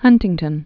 (hŭntĭng-tən)